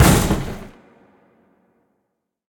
tableSlamNoBox.ogg